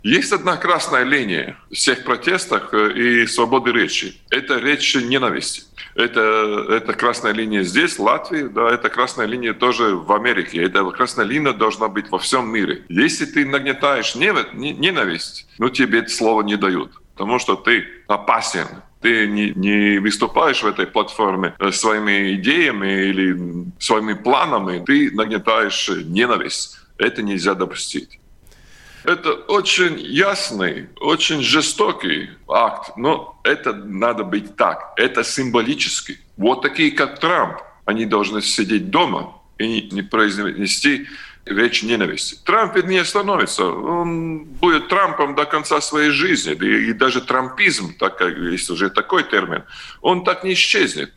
Экс-президент Латвии был в гостях на радио Baltkom и в ходе эфира поддержал блокировку Трампа.